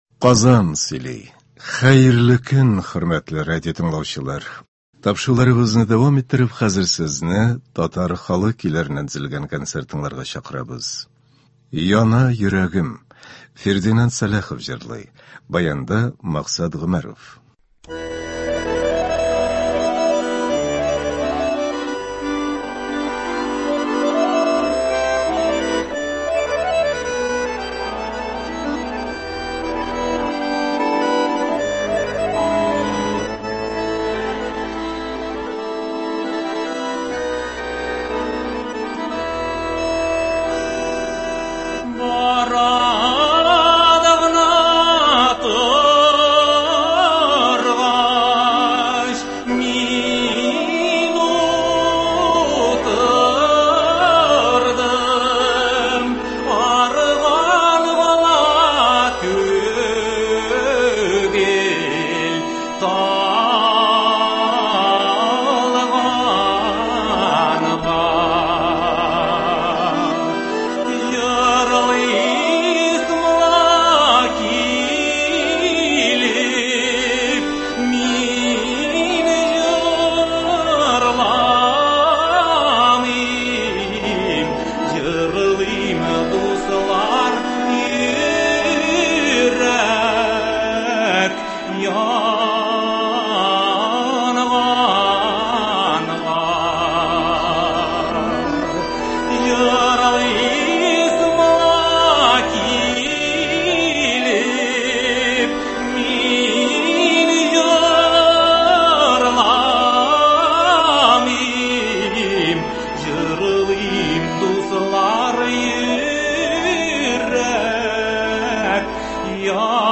Татар халык көйләре (13.08.22)